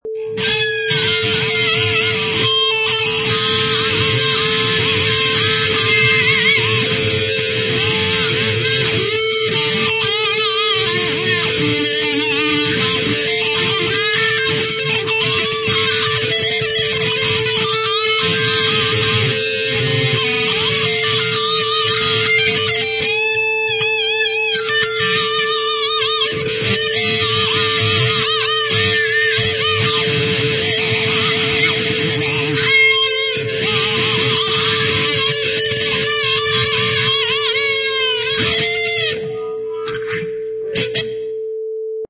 Sound files: Distorted Guitar Solo 87 bpm in Cm Loop 5
Rawking distorted electric guitar solo LOOP
Product Info: 44.1k 24bit Stereo
Category: Musical Instruments / Guitars / Electric
Relevant for: electric, guitar, rock, n, distorted, distortion, heavy, metal, alternative, loop, loops, instrument, chord, chords, dark, minor, hard, noise, feedback, .
Try preview above (pink tone added for copyright).
Distorted_Guitar_Solo_87_bpm_in_Cm_Loop_5.mp3